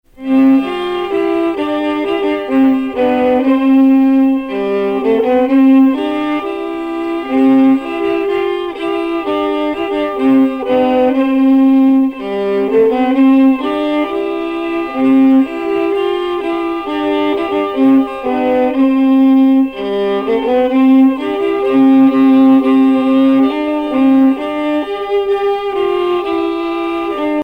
circonstance : fiançaille, noce